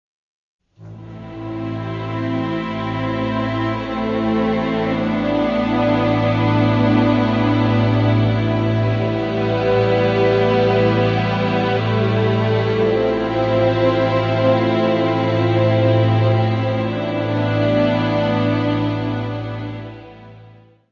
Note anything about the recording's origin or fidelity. : stereo; 12 cm + folheto Gravado no Angel Recording Studios, Londres